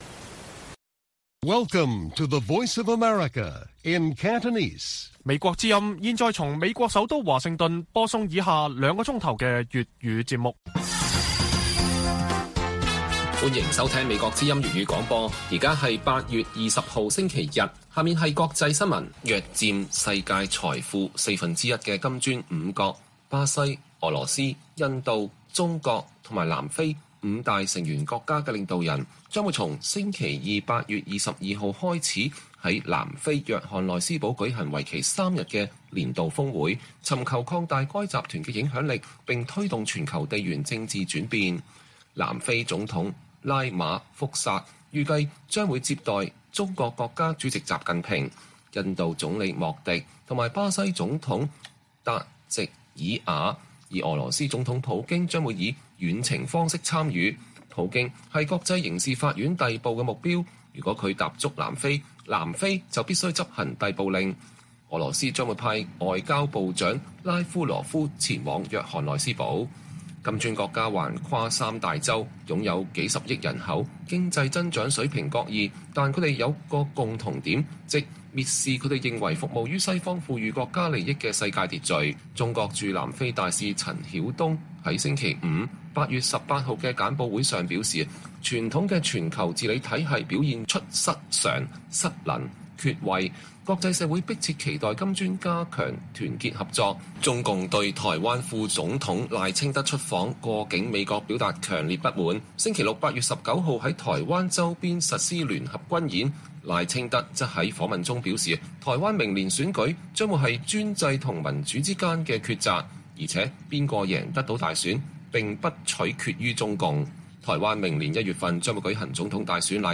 粵語新聞 晚上9-10點 : 美中恢復商貿高層會談，專家：北京應抓住改善關係契機